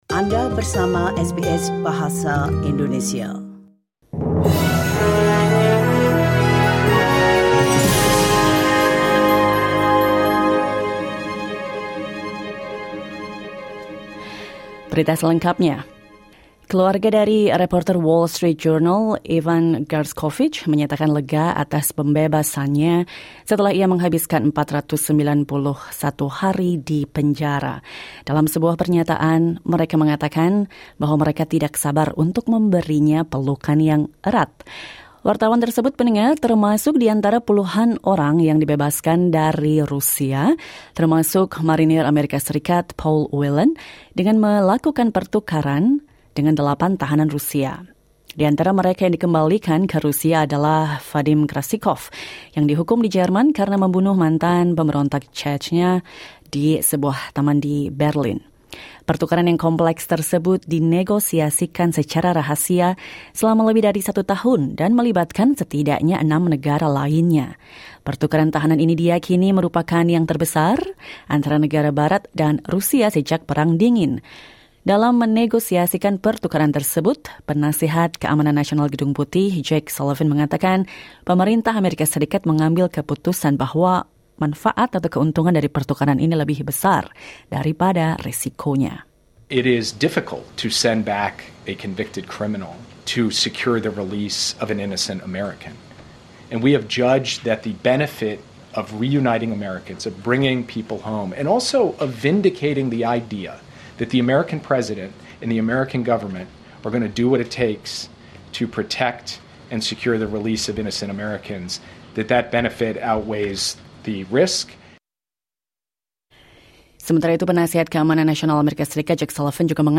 SBS Indonesian news